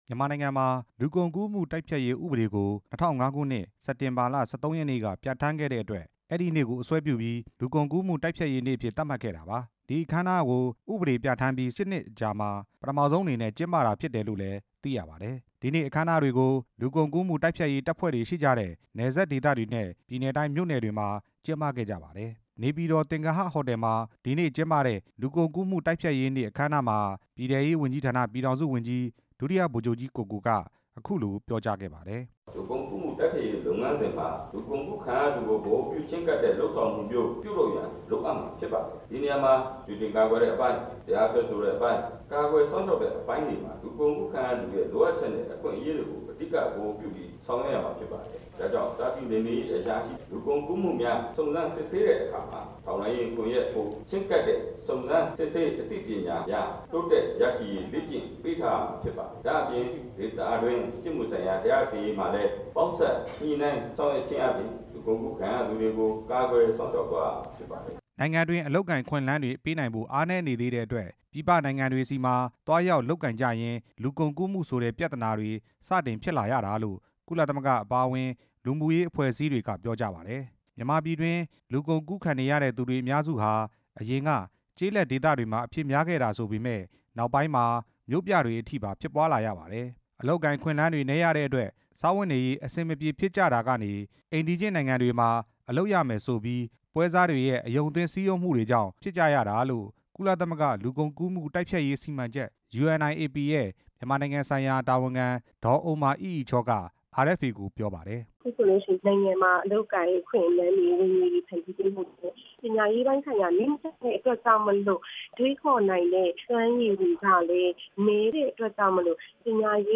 နိုင်ငံတကာလူကုန်ကူးမှု တိုက်ဖျက်ရေးနေ့ အခမ်းအနားများတင်ပြချက်